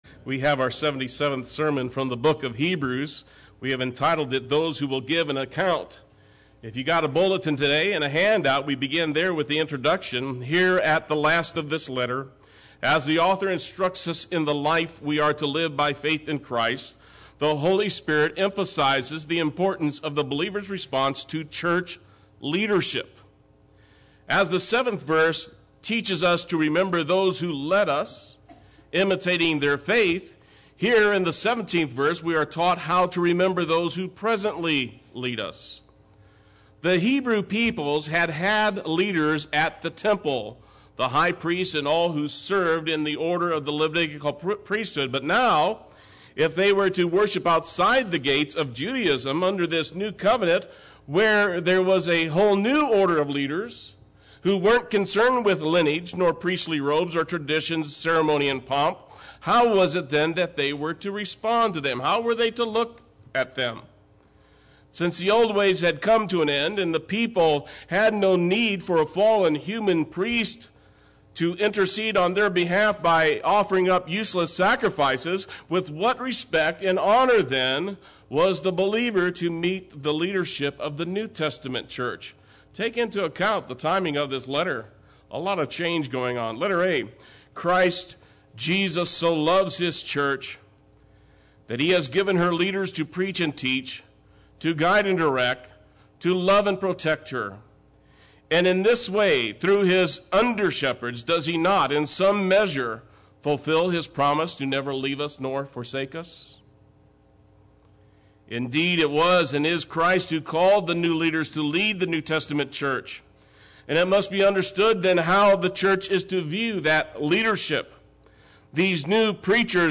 Play Sermon Get HCF Teaching Automatically.
Those Who Will Give an Account Sunday Worship